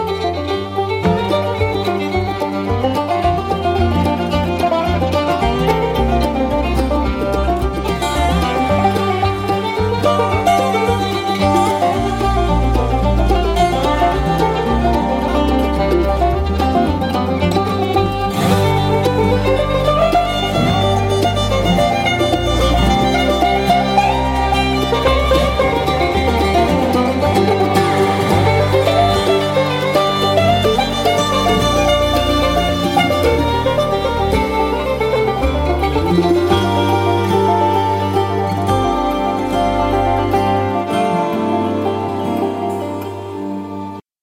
Bodrhan
Double bass
Irish traditional fiddle & 5 string banjo